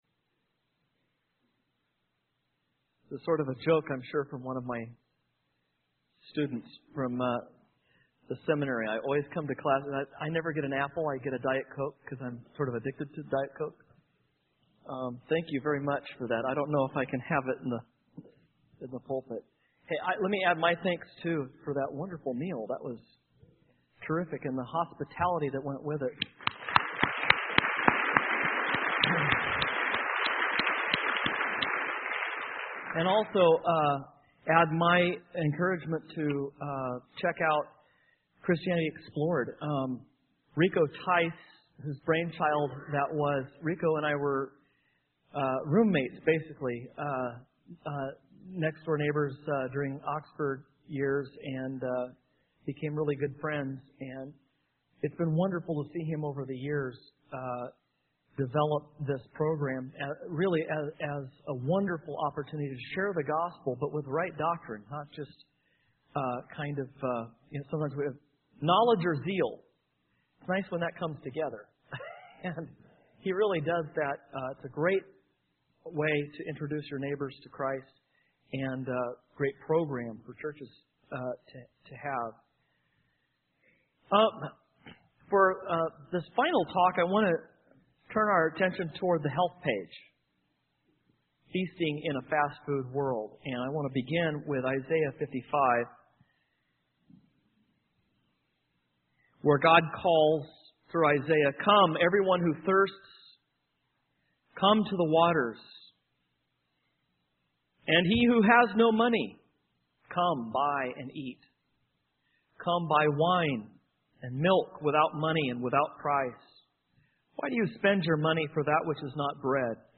In this sermon, the speaker emphasizes the importance of objective facts and experiences grounded in historical events and objective states of affairs. He contrasts these with fleeting and superficial experiences, such as watching movies or riding roller coasters.